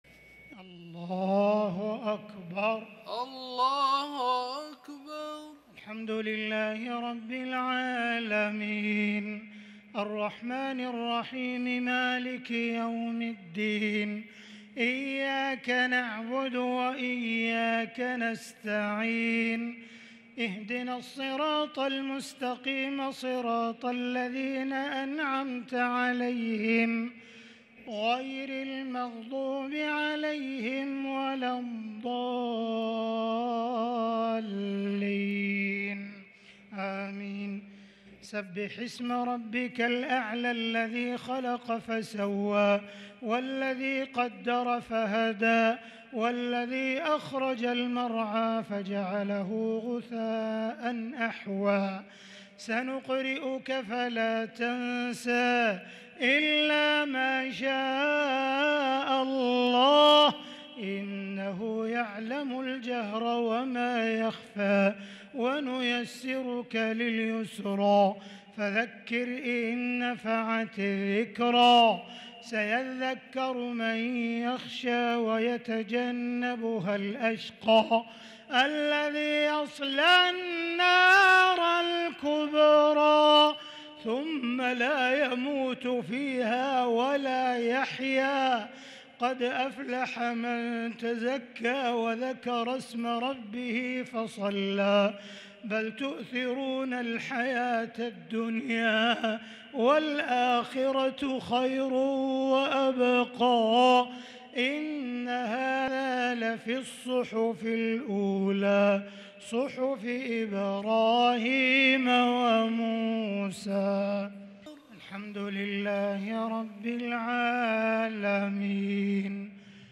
الشفع و الوتر ليلة 23 رمضان 1443هـ | Witr 23 st night Ramadan 1443H > تراويح الحرم المكي عام 1443 🕋 > التراويح - تلاوات الحرمين